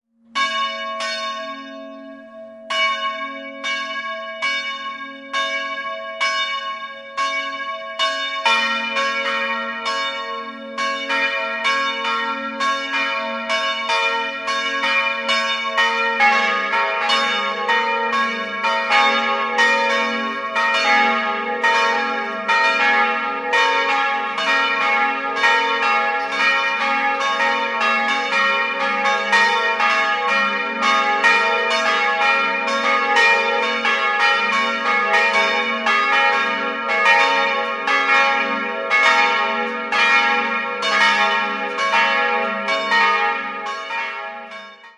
3-stimmiges Gloria-Geläute: as'-b'-des''